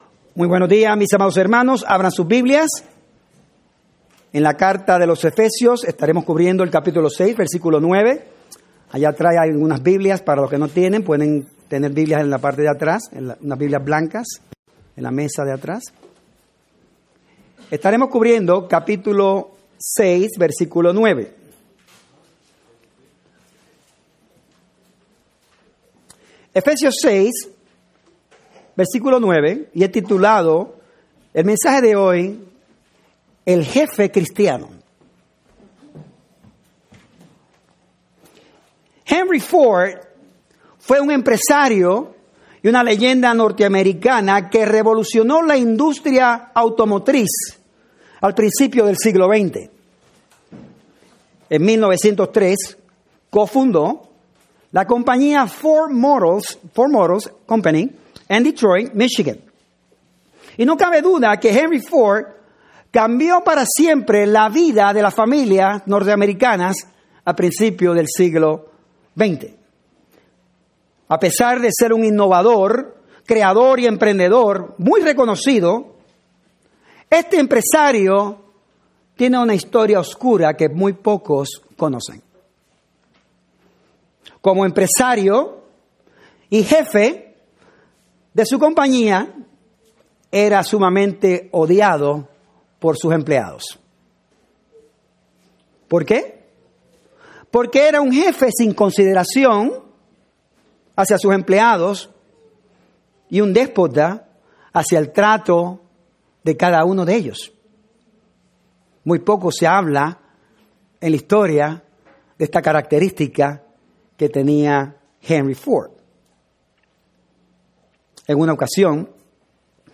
Spanish Ephesians Bible Study